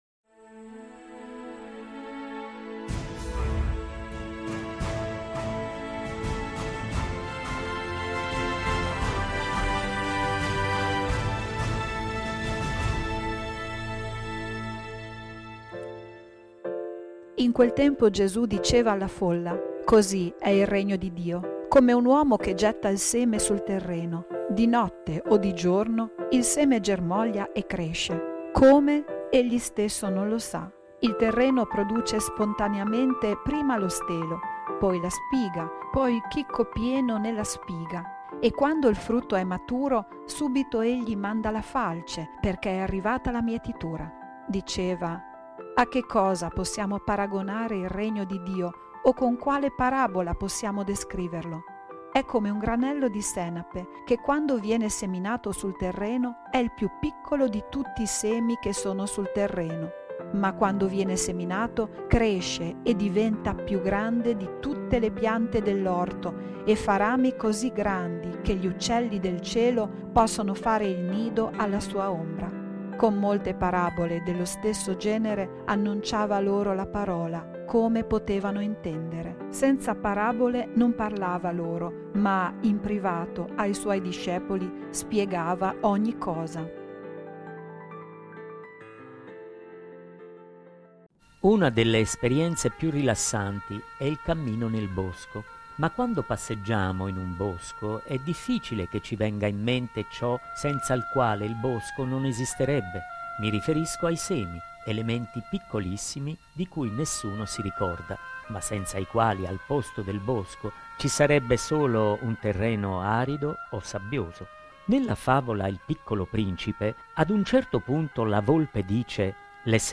Dal Vangelo secondo Marco (Mc 4 26 34) Con una riflessione di don Erio Castellucci